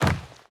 Wood Land.ogg